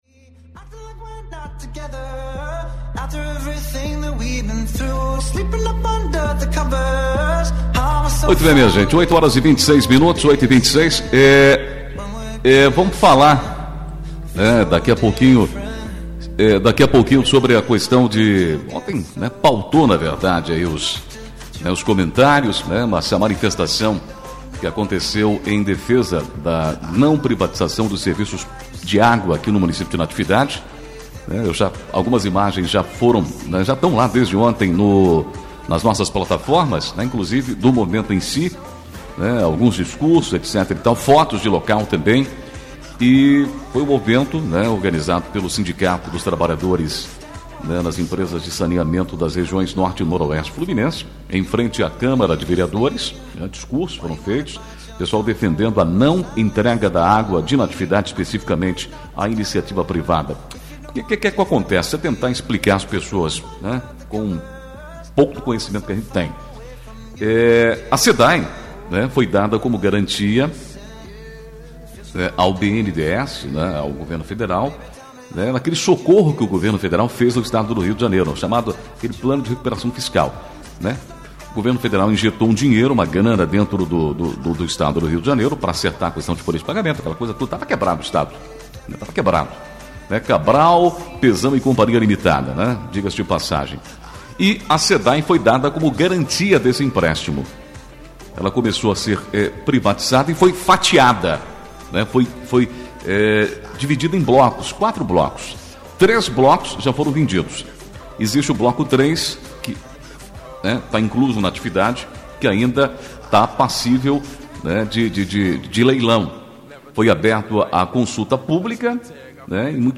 8 outubro, 2021 ENTREVISTAS, NATIVIDADE AGORA